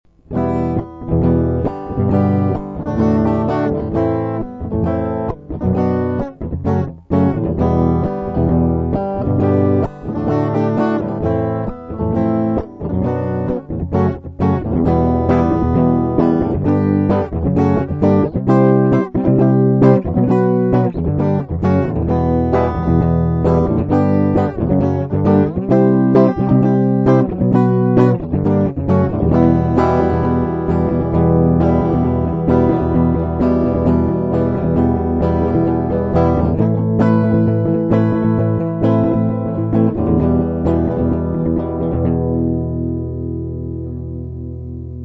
- вступление и проигрыш